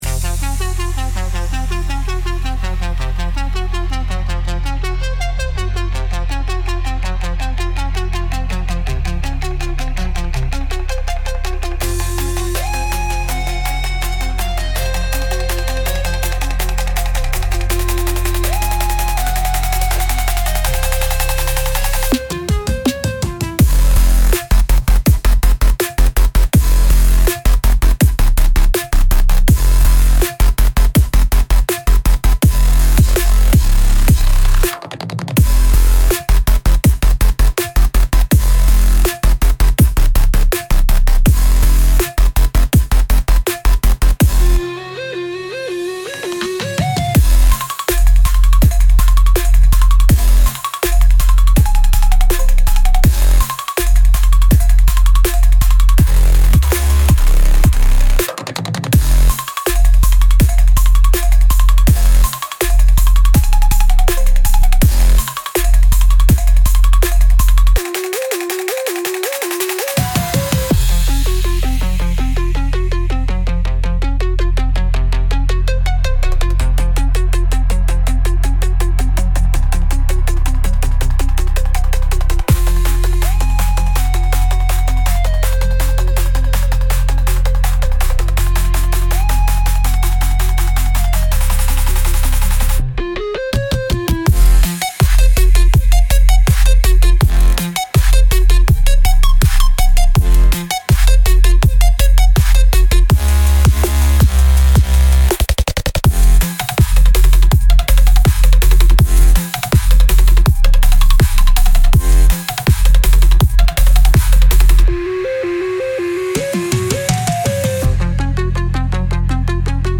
Instrumental - Fire tongue Funk